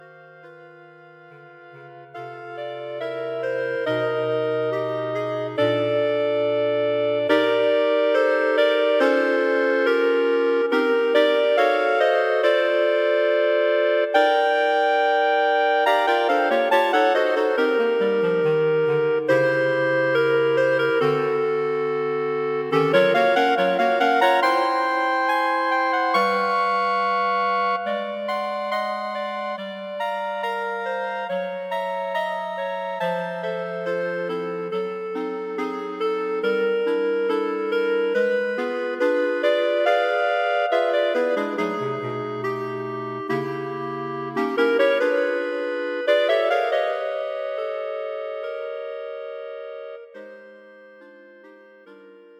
Clarinet Quartet
Three Clarinets in Bb and Bass Clarinet.
Modern pieces bordering on jazz.